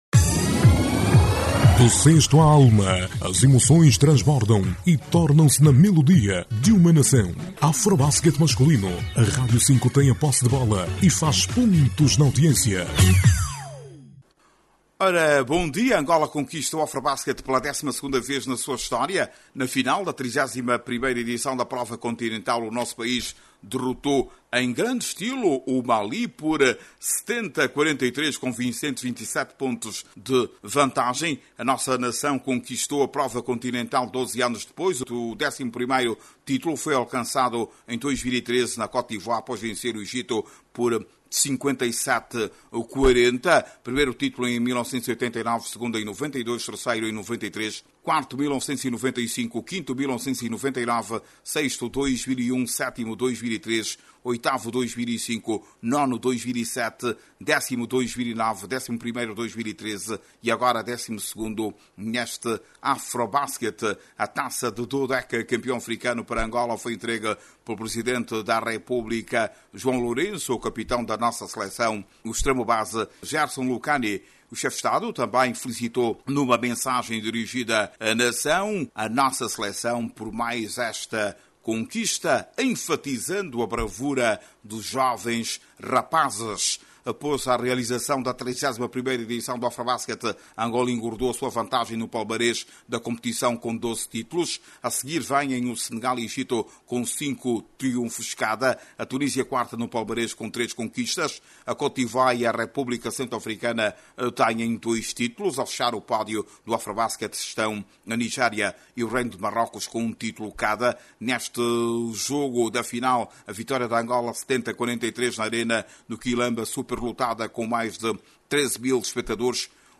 A selecção que 12 anos depois regressa à consagração continental venceu na final o Mali por 70/43, exatamente 27 pontos de vantagem. Clique no áudio abaixo e ouça a reportagem